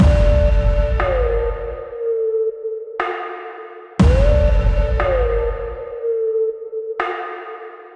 music-loop-2.wav